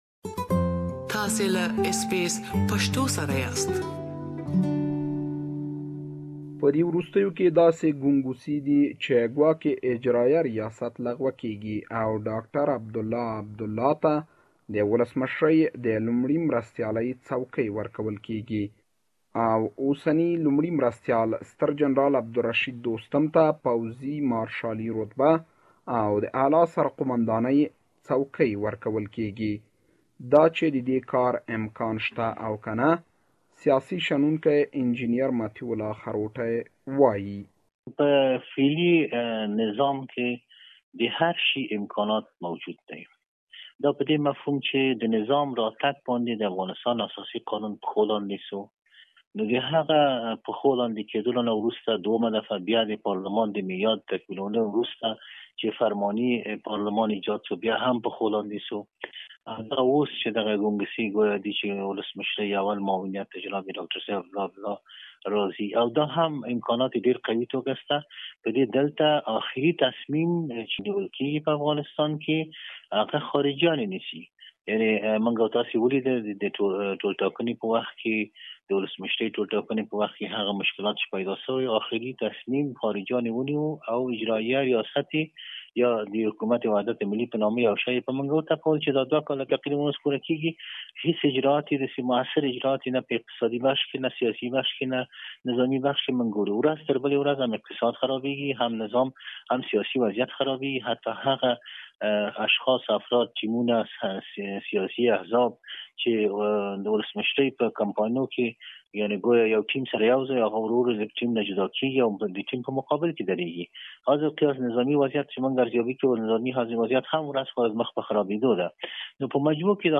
We have interviewed some experts who share their view on such move.